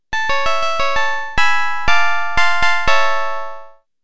I have a simple virtual analog synthesizer going that can be used to synthesize piano-like sounds.
Sample 3 (A minor)
sample-aminor.mp3